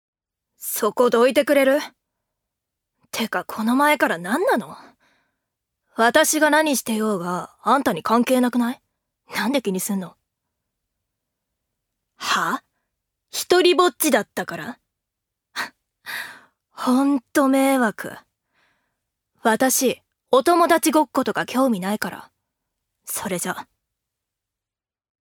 女性タレント
音声サンプル
セリフ２